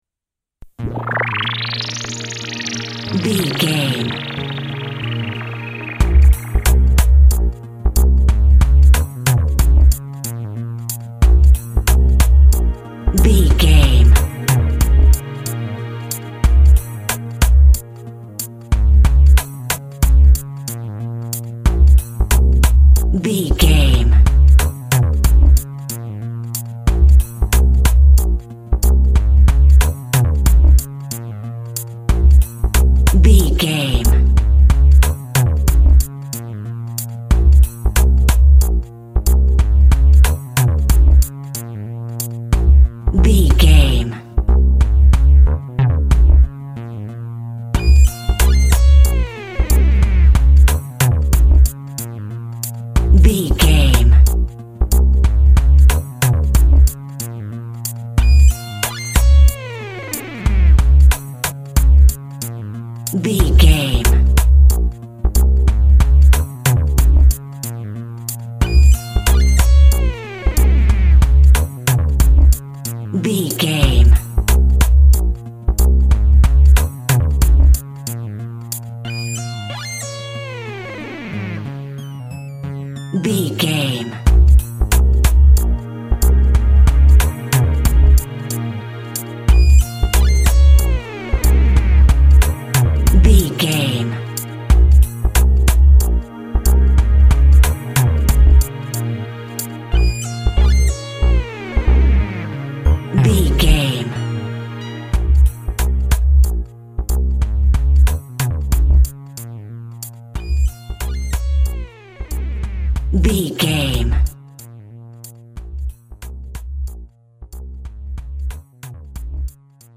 Aeolian/Minor
B♭
hip hop
turntables
synth lead
synth bass
hip hop synths
electronics